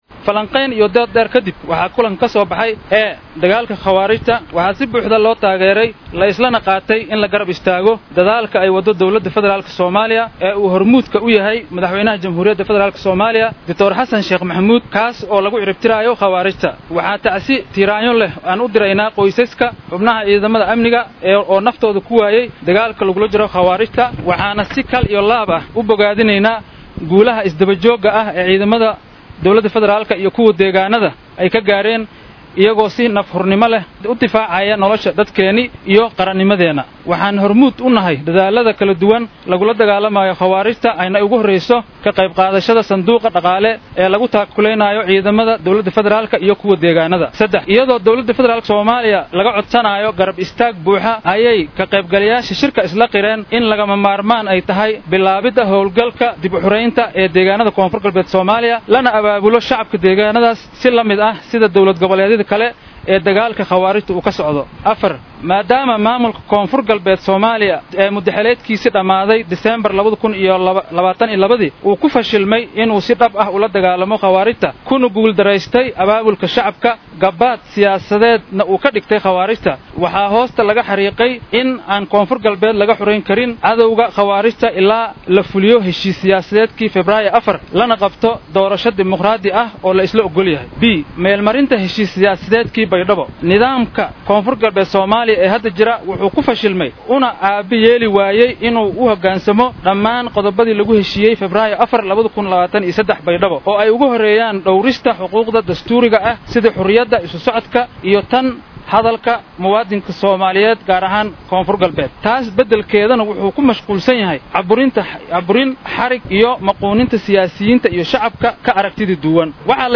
Siyaasiyiintan ayaa shirkooda ka soo saaray war murtiyeed ka kooban dhowr qodob oo qaarkood dhaliilo adag ay ugu jeediyeen maamulka Koonfur Galbeed. Bayaanka ayaa sidatan saxaafadda loogu akhriyay